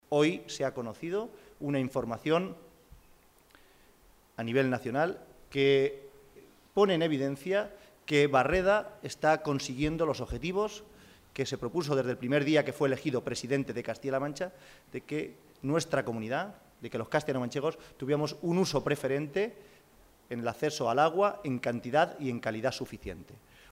Momento de la rueda de prensa celebrada en la sede del PSOE de Albacete